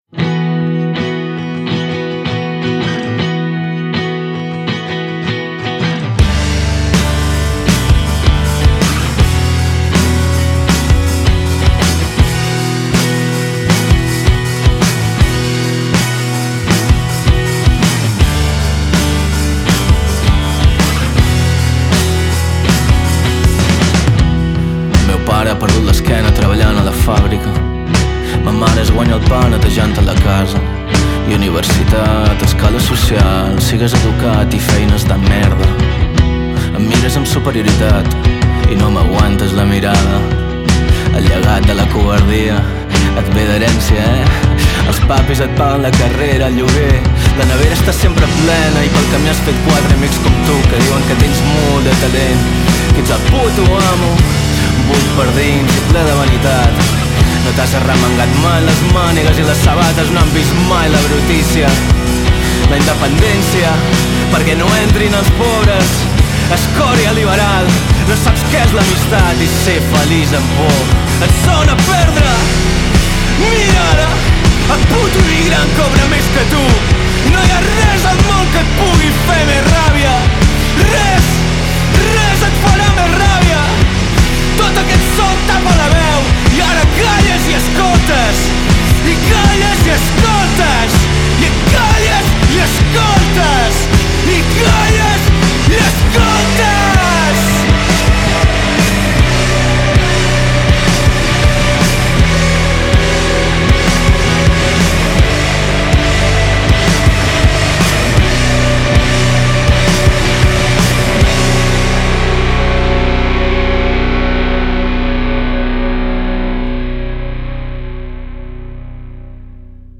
Genres: Indie Rock, Alternative Rock